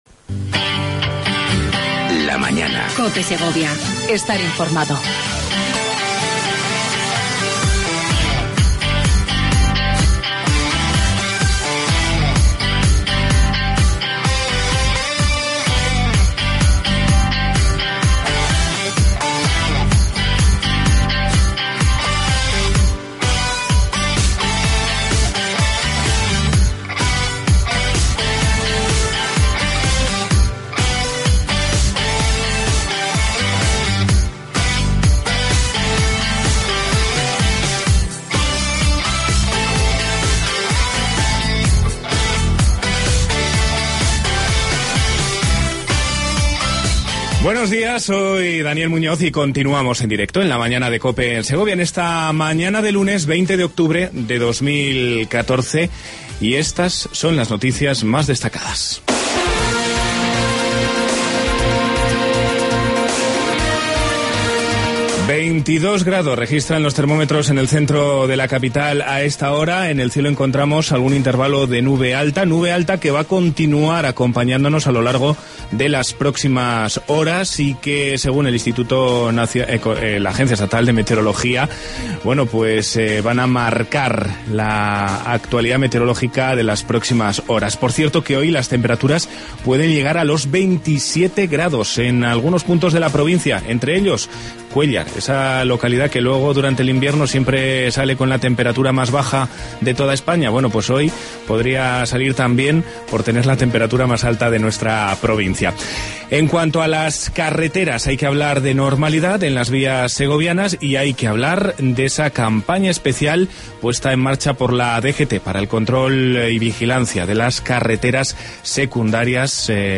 AUDIO: Entrevista con Javier Lopez Escobar, Delegado De La Junta de Csstilla y León.